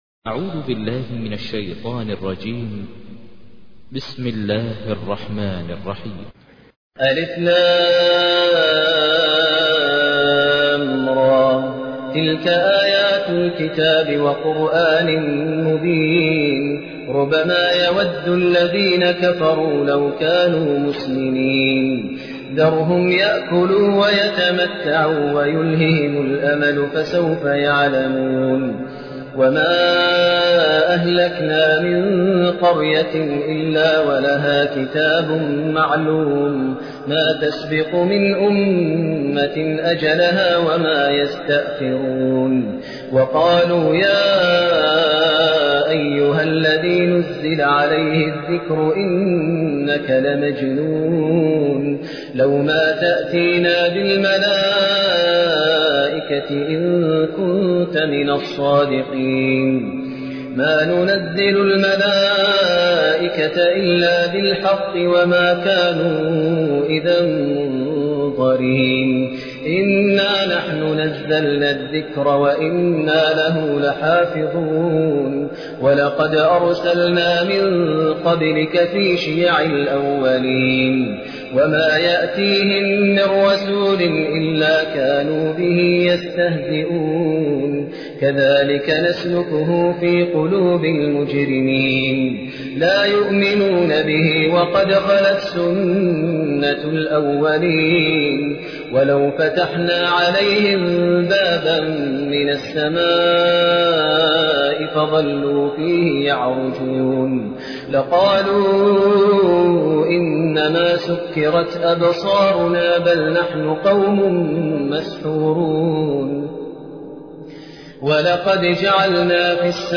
تحميل : 15. سورة الحجر / القارئ ماهر المعيقلي / القرآن الكريم / موقع يا حسين